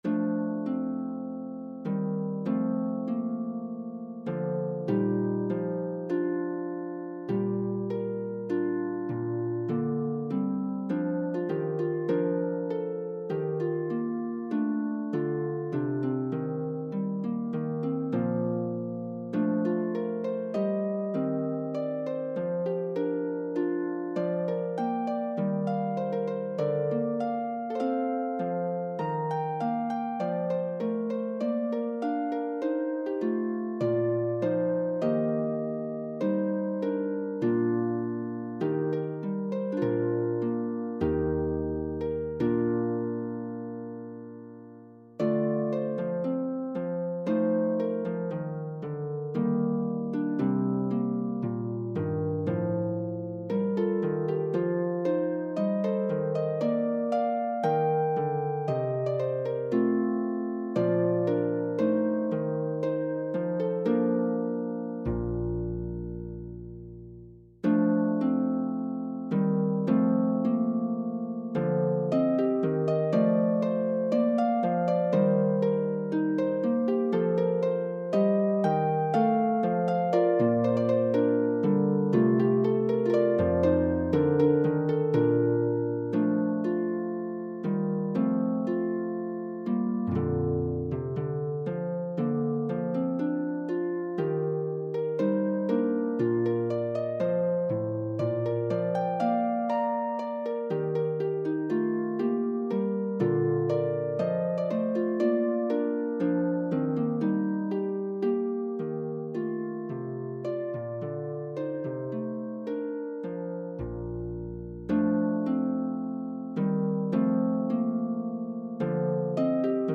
is for solo lever or pedal harp
Played very slowly